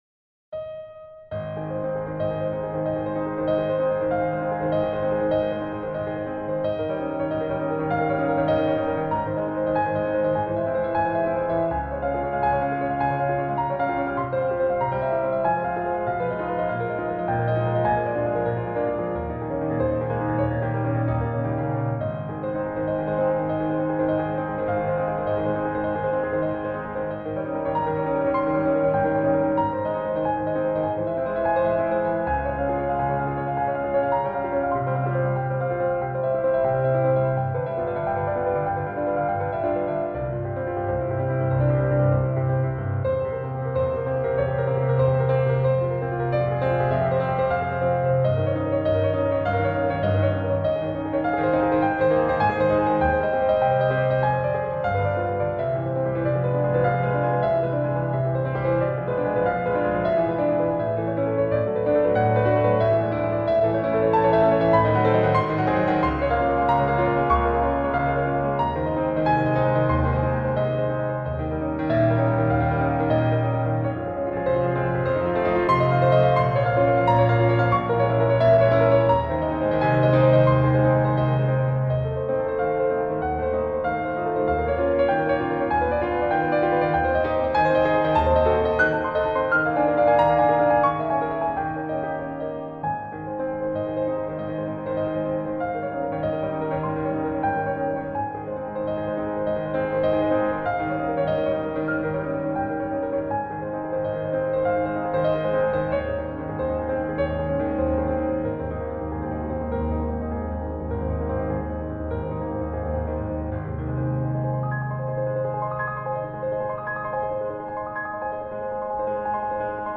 Piano Classical
Instrument: Piano
Style: Classical